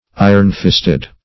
Search Result for " iron-fisted" : The Collaborative International Dictionary of English v.0.48: ironfisted \i"ron*fist`ed\, iron-fisted \i"ron-fist`ed\, a. 1.
iron-fisted.mp3